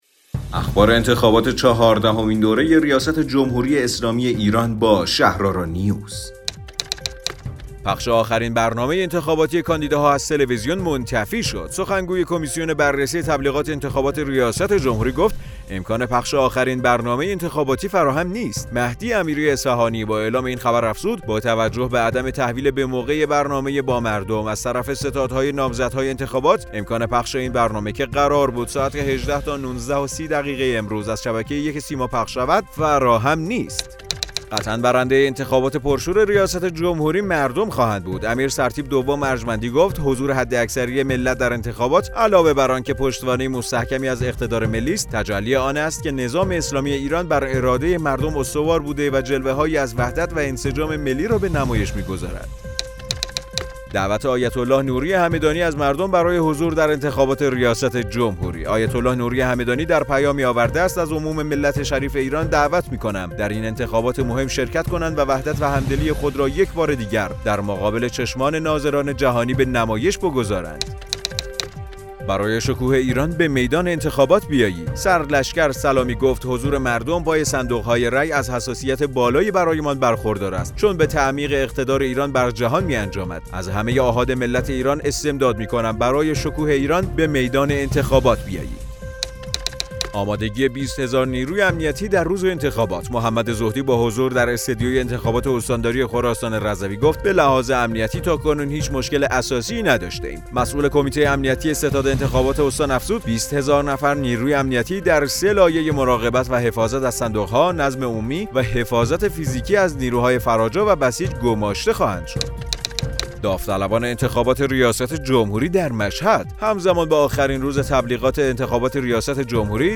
رادیو شهرآرا، پادکست خبری انتخابات ریاست جمهوری ۱۴۰۳ است.